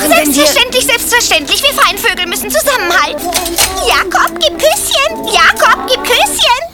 Ein paar Samples aus dem Trickfilm Oliver & Olivia, Anfang 1992 in Berlin synchronisiert.
Papagei